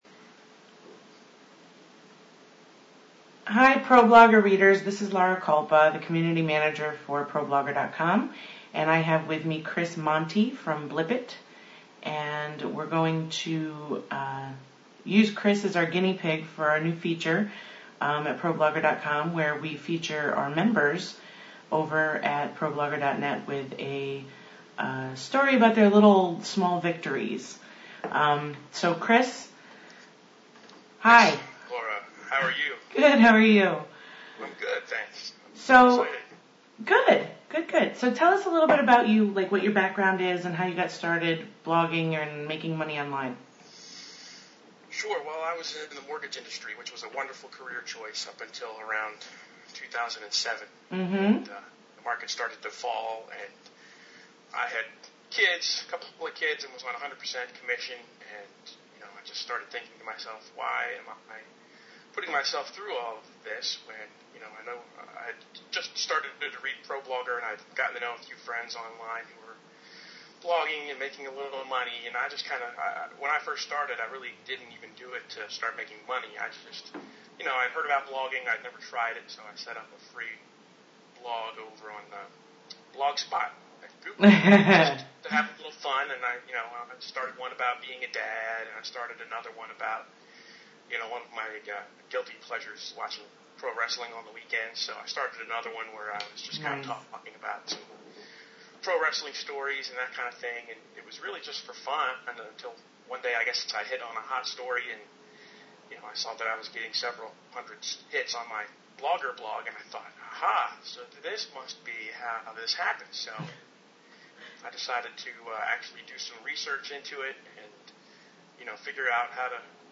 Transcription of Interview